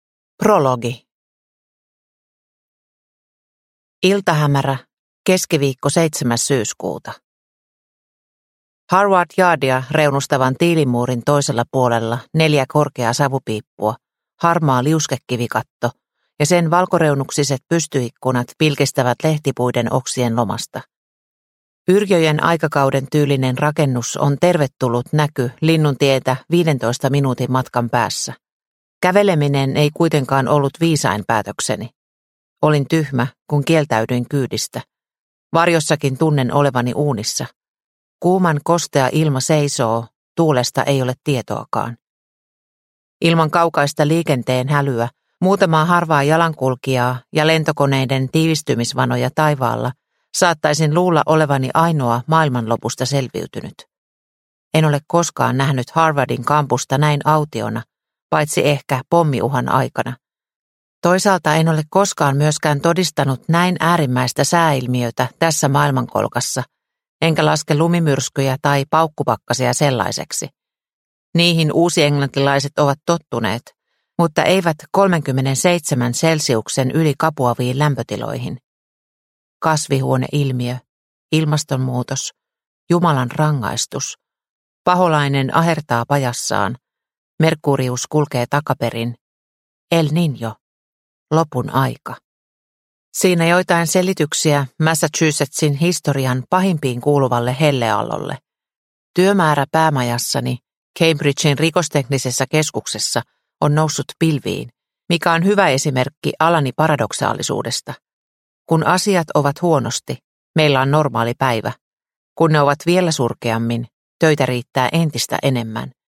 Kaaos – Ljudbok – Laddas ner